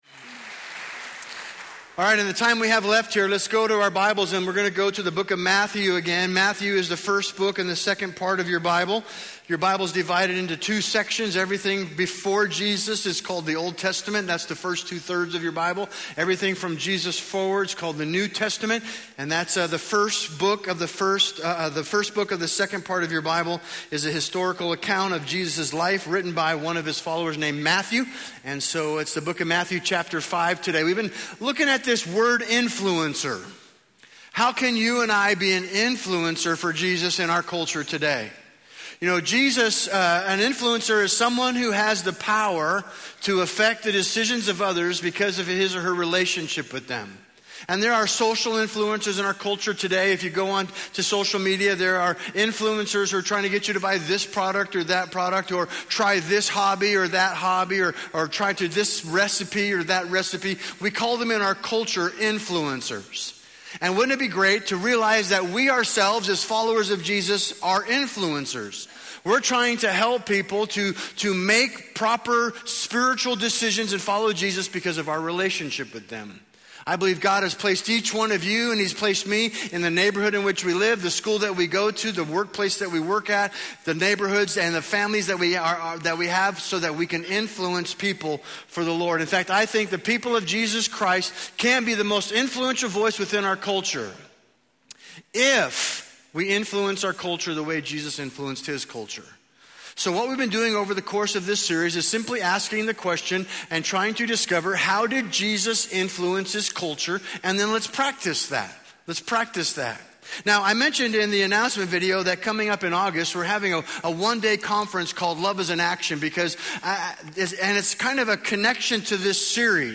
A message from the series "Influencer."